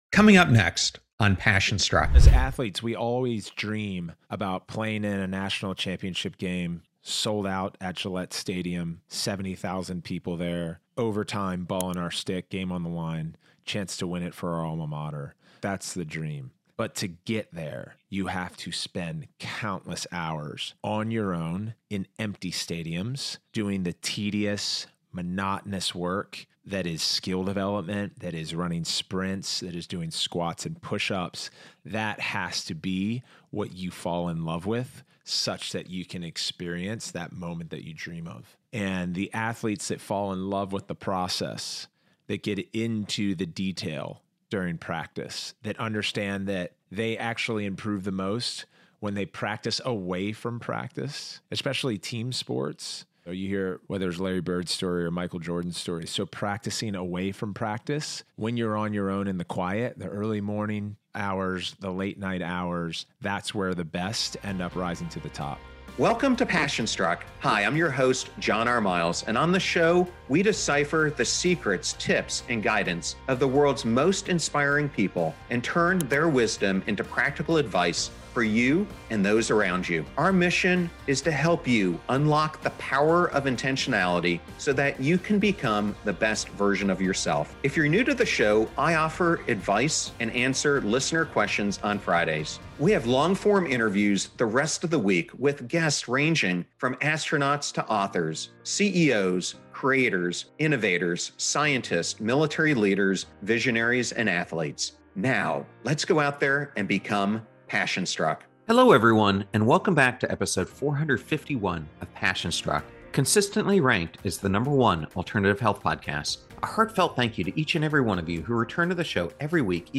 Welcome to another compelling episode of the podcast broadcast on 96.7 FM RadioStPete Thursdays at 6pm!
Get ready for a riveting conversation filled with wisdom, inspiration, and practical advice on achieving success both in sports and in life.